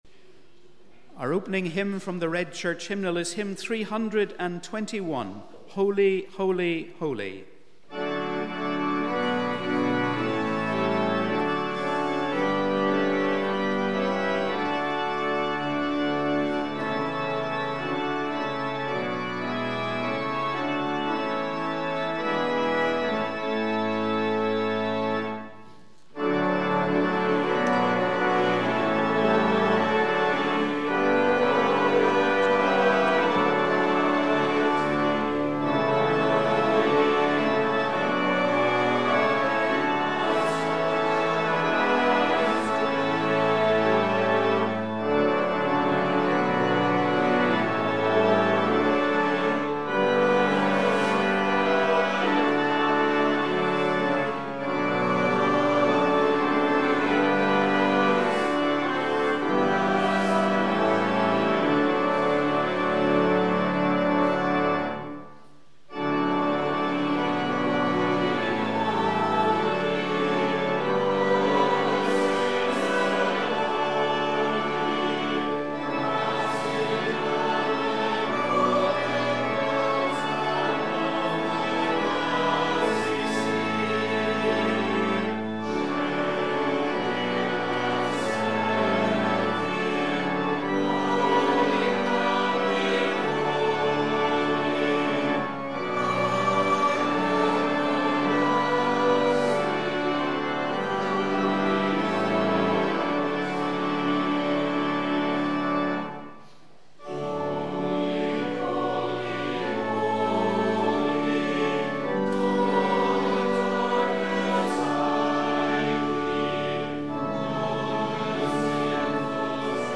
Welcome to our service of Holy Communion on the 4th Sunday before Lent.
Holy-Communion-Sunday-9th-February-2025.mp3